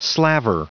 Prononciation du mot slaver en anglais (fichier audio)
Prononciation du mot : slaver